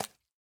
sounds / mob / frog / step2.ogg